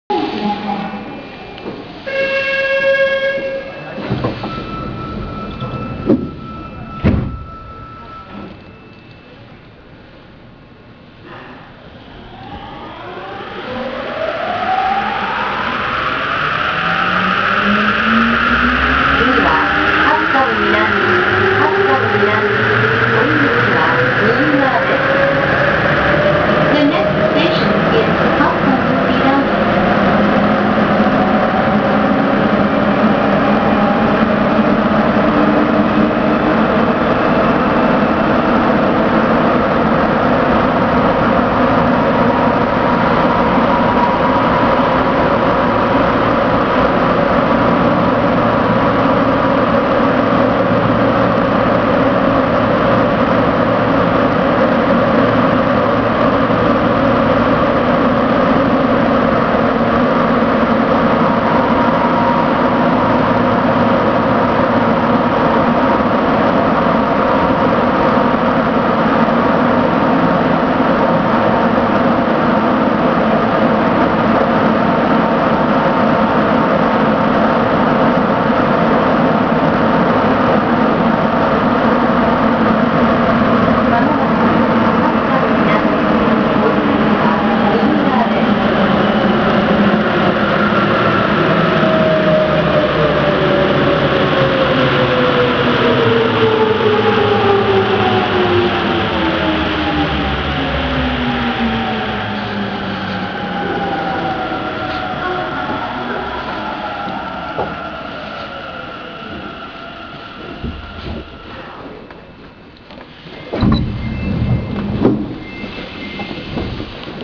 ・8000形（三菱IGBT）走行音
【東西線】西18丁目〜西11丁目（1分33秒：550KB）
すぐにゴムタイヤの音にモーター音が掻き消されてしまうものの、なんとなく三菱らしさはわかる起動音をしている気がします。